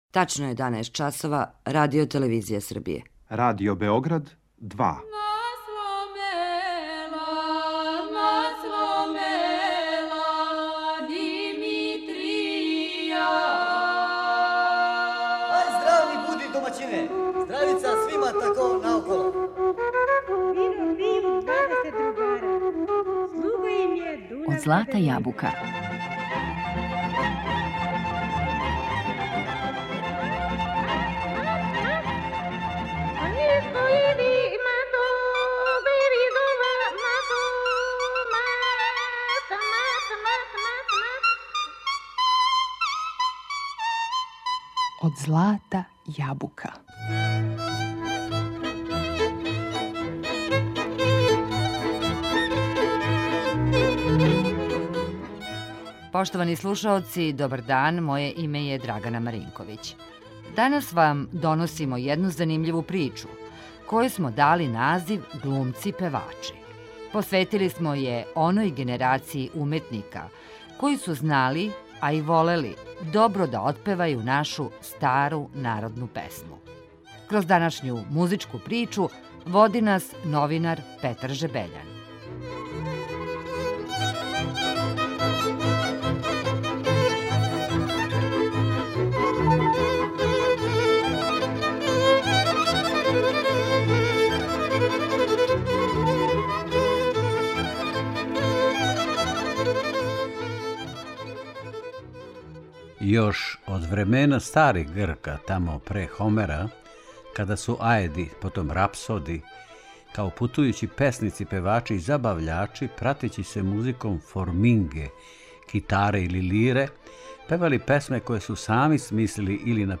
Посветили смо је оној генерацији уметника који су знали, умели, а и волели добро да отпевају нашу стару градску и народну песму.